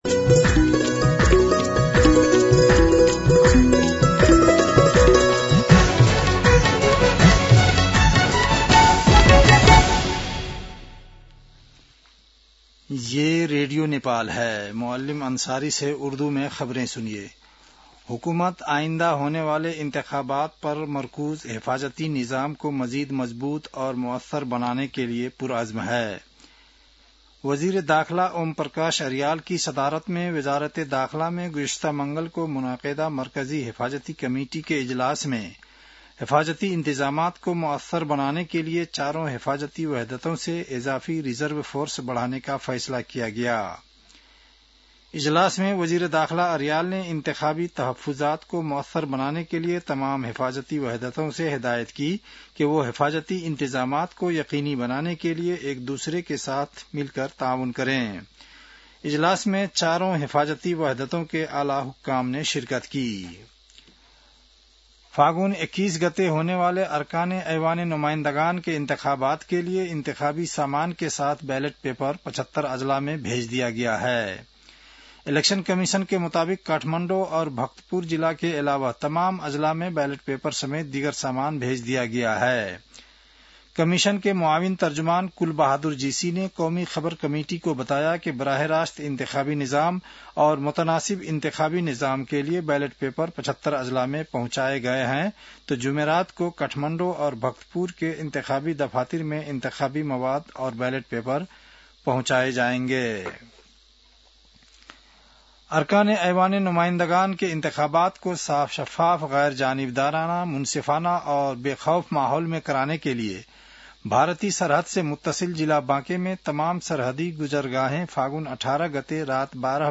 उर्दु भाषामा समाचार : १३ फागुन , २०८२